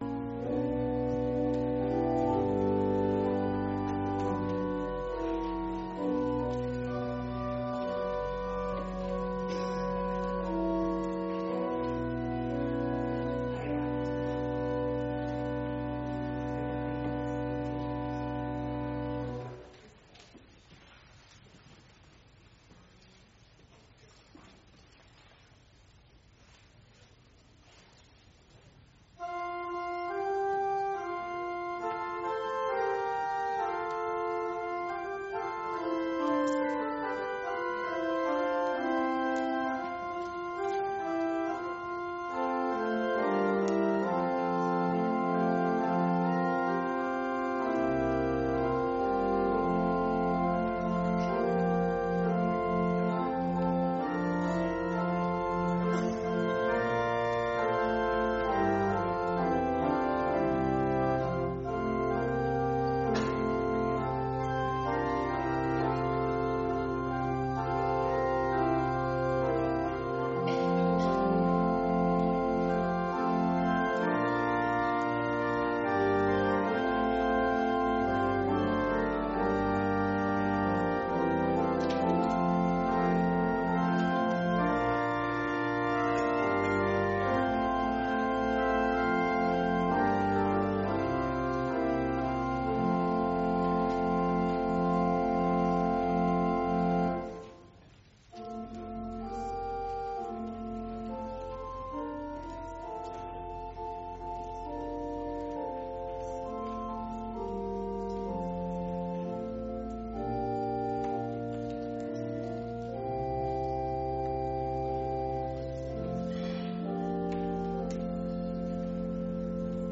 Ministry of the Word in Philemon 8-16
Service Type: Sunday Morning